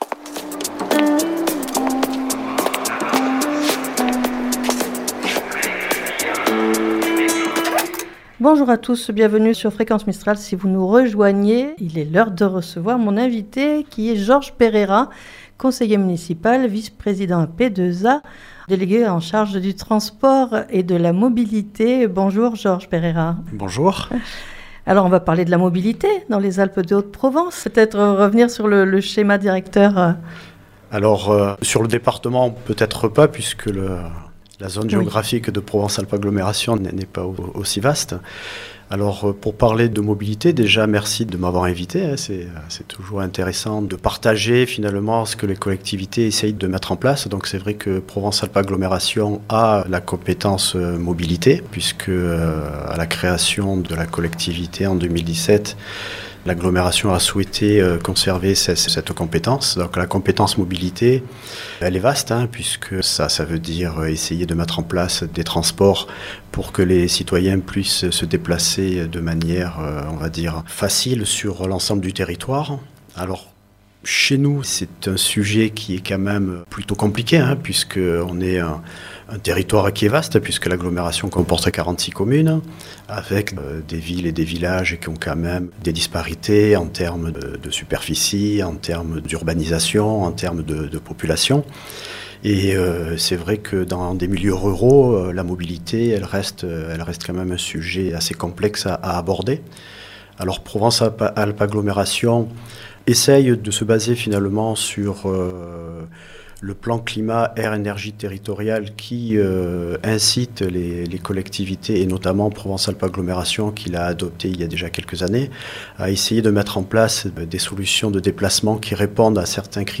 Georges Pereira Vice-président à Provence Alpes Agglomération, délégué à la mobilité et aux politiques de la ville de Digne les Bains, est notre invité pour nous en parler au micro